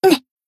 贡献 ） 分类:蔚蓝档案语音 协议:Copyright 您不可以覆盖此文件。
BA_V_Hina_Swimsuit_Battle_Damage_1.ogg